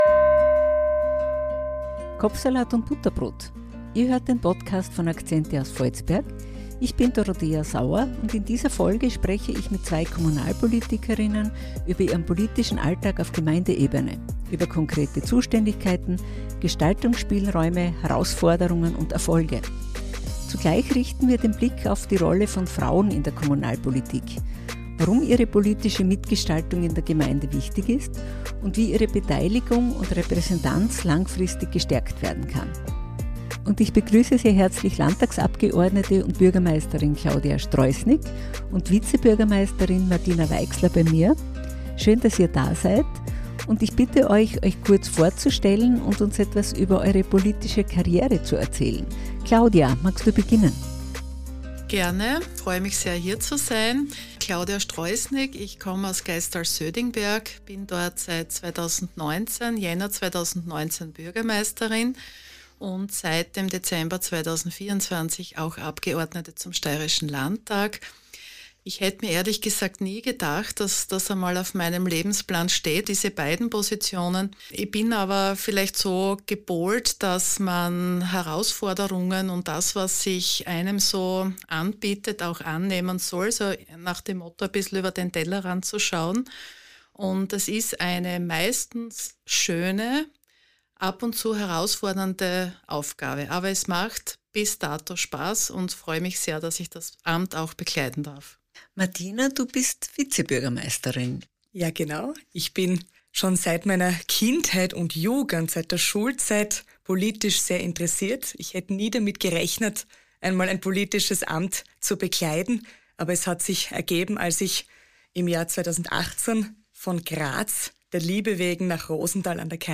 Bürgermeisterin und Landtagsabgeordnete Klaudia Stroßnig und Vizebürgermeisterin Martina Weixler geben Einblick in die Zuständigkeiten und Aufgaben in der Kommunalpolitik und sprechen über Herausforderungen und Chancen von Frauen in der Kommunalpolitik.